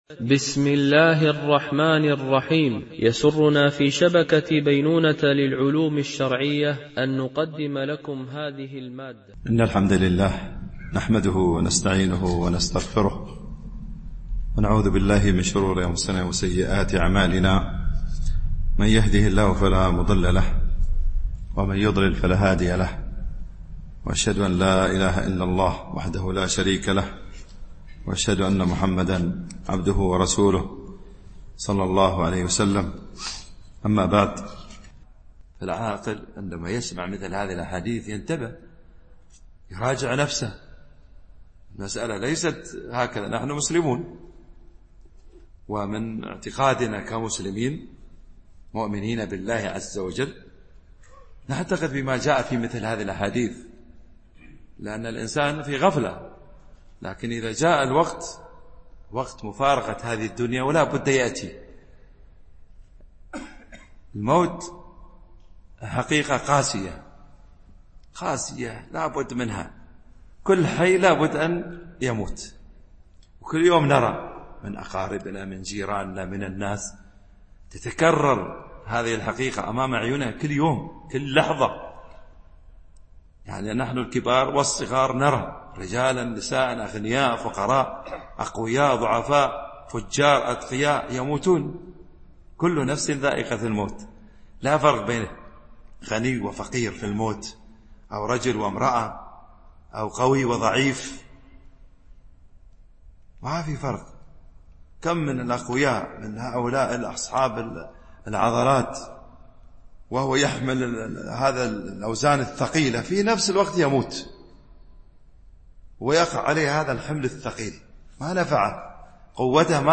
موعظة في الموت وما بعده